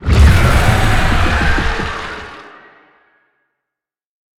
Sfx_creature_shadowleviathan_roar_afterdeath_01.ogg